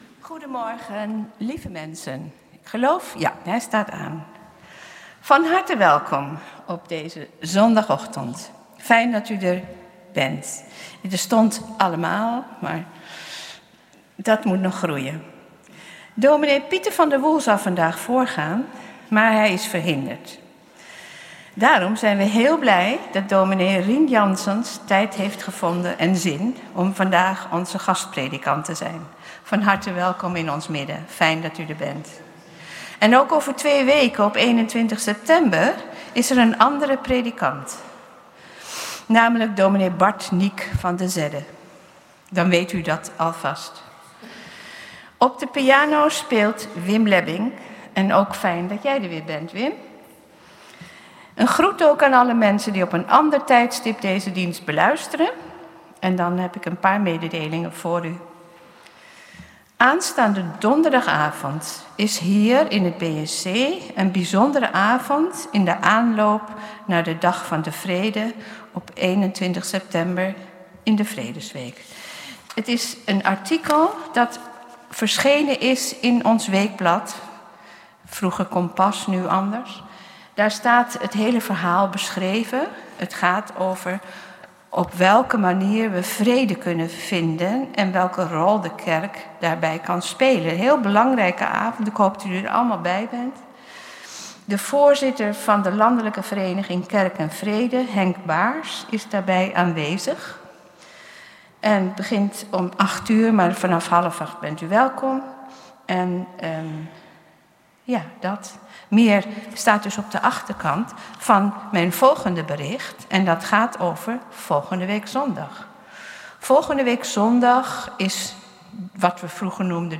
Protestantse Gemeente Oostzaan - Zondag 10.00 uur Kerkdienst in de Grote Kerk!
Kerkdienst geluidsopname